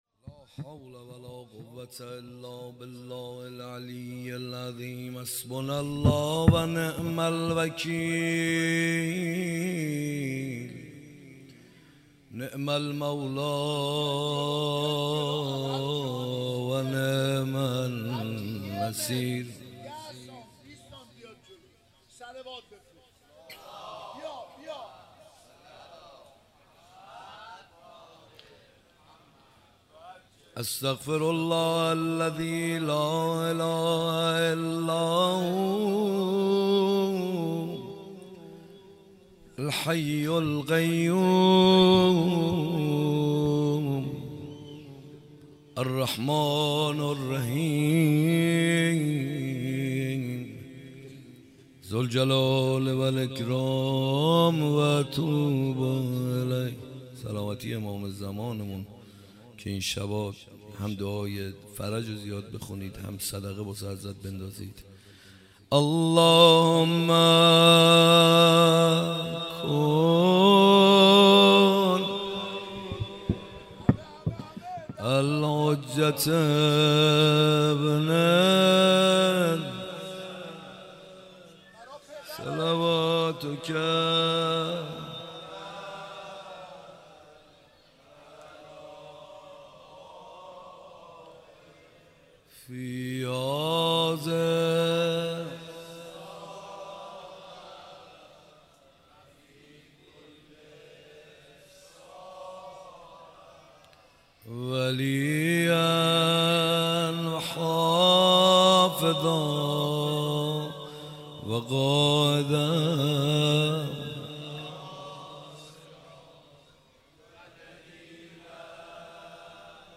روضه شب عاشورا